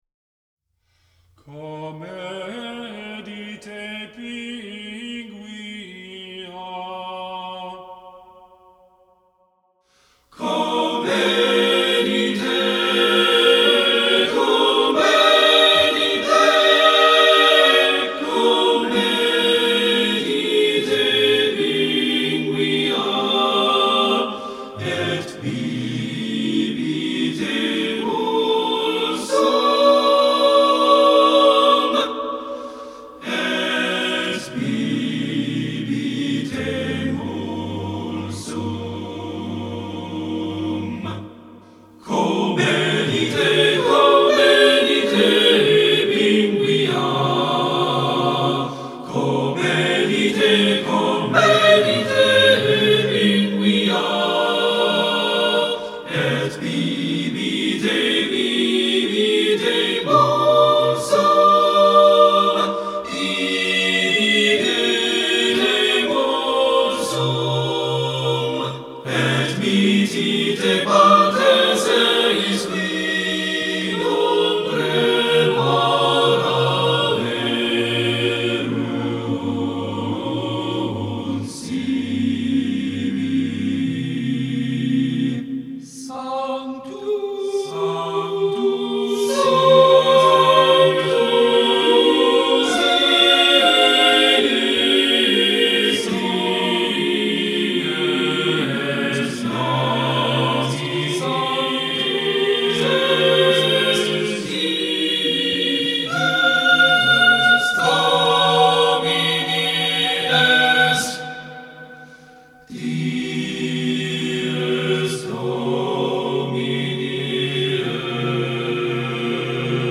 10 Number of voices: 4vv Voicing: SATB Genre: Sacred, Motet, Communion for OT 3C
Language: Latin Instruments: A cappella